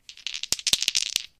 dieShuffle2.ogg